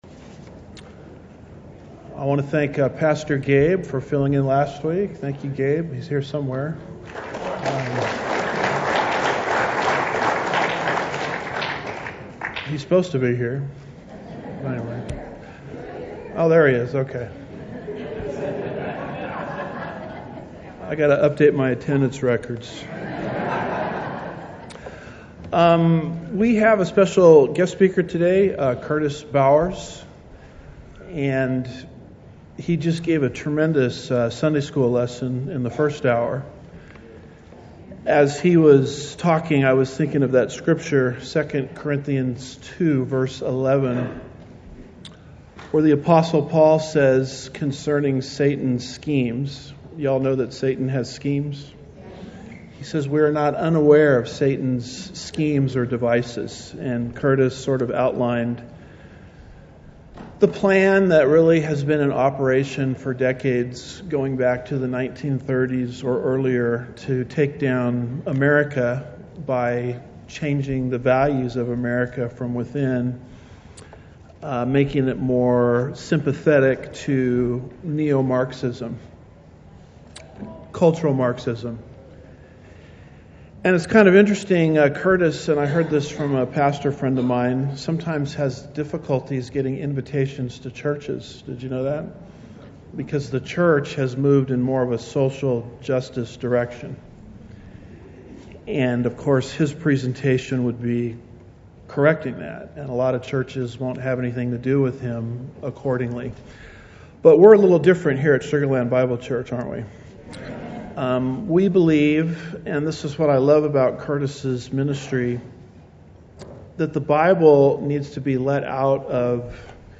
Sermons
Guest Speaker